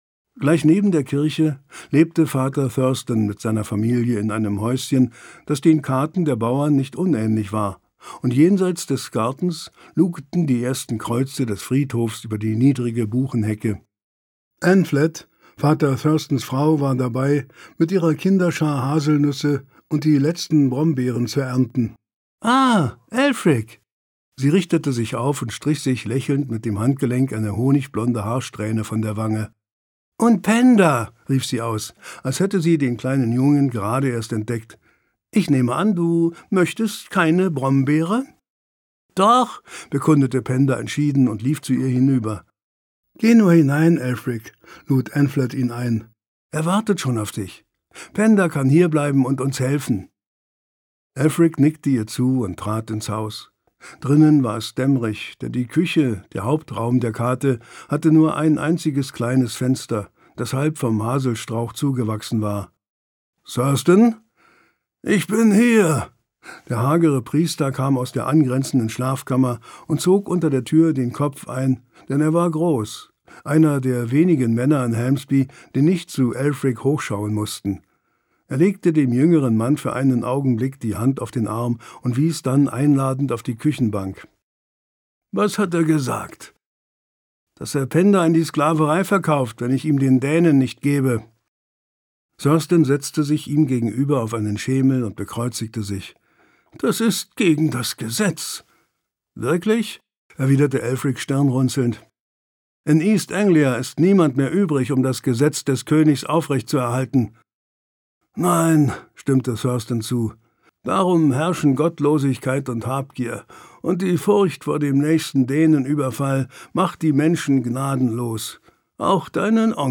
Detlef Bierstedt (Sprecher)